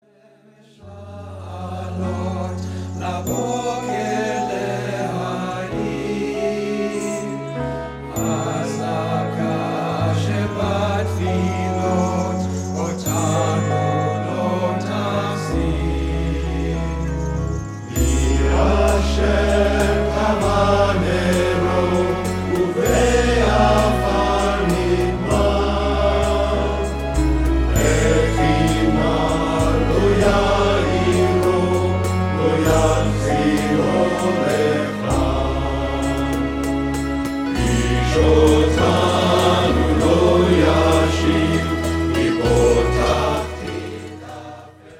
Indian musical instruments